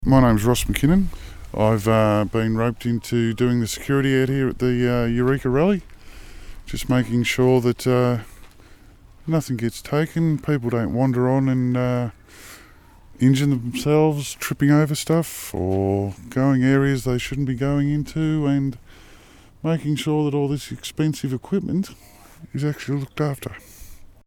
RADIO GRABS